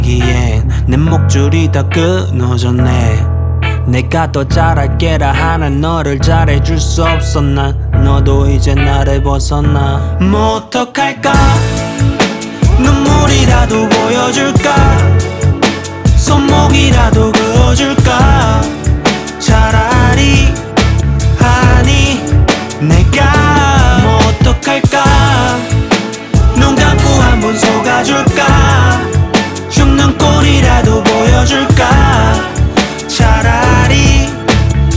Жанр: K-pop / Поп